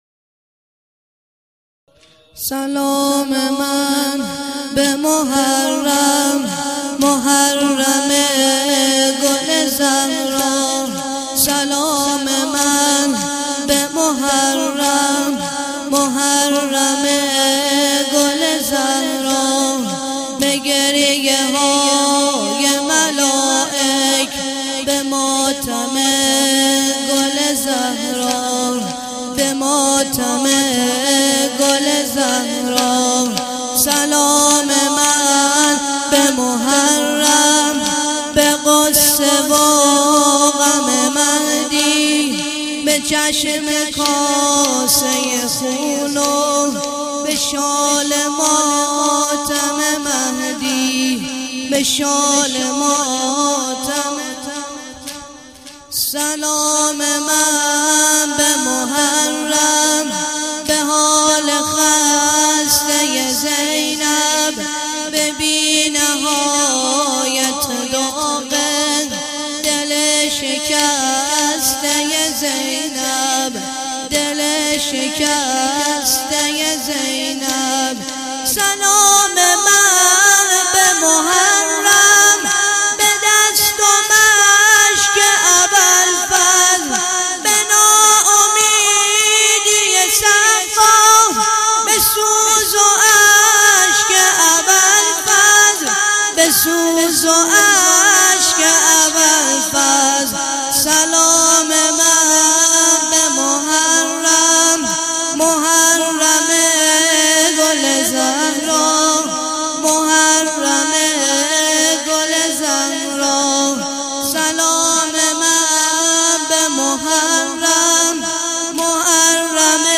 محرم 1393 - مداح